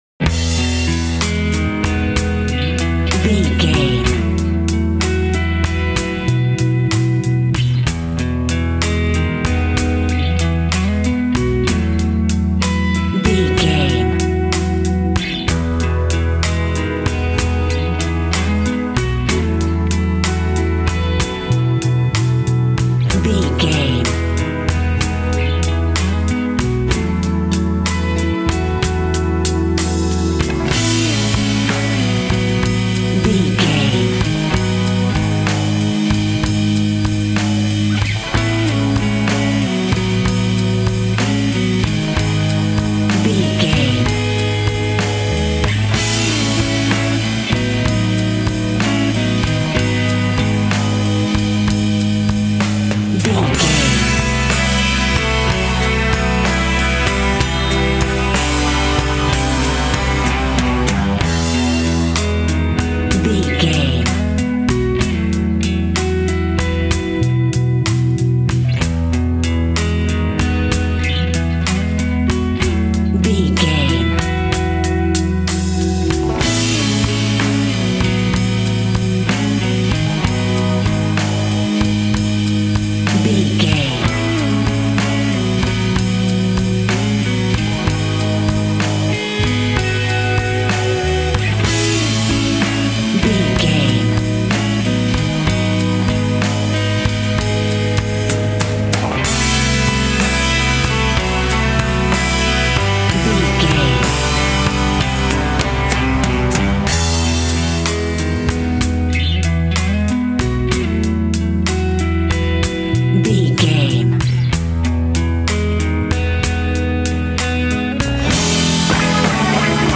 Good times, relaxing, friends, party,
Ionian/Major
Slow
drums
electric guitar
bass guitar
relaxing